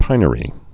(pīnə-rē)